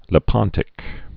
(lĭ-pŏntĭk)